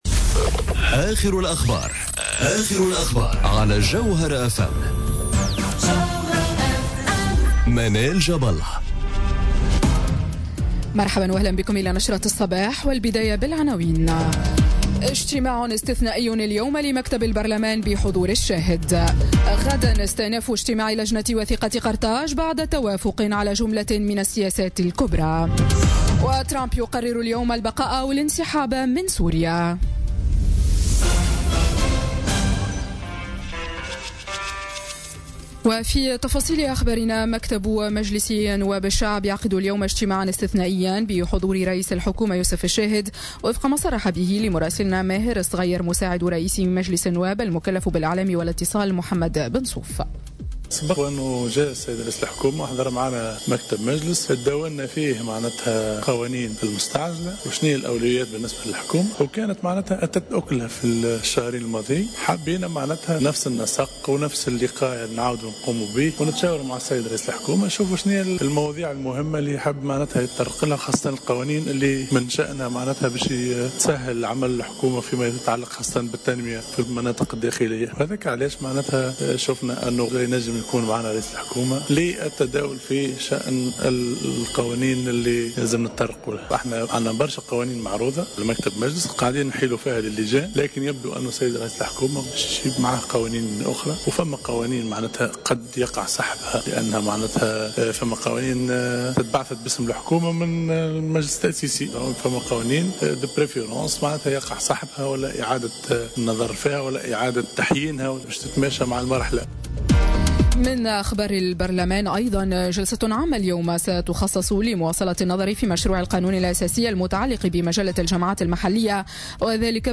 نشرة أخبار السابعة صباحا ليوم الثلاثاء 3 أفريل 2018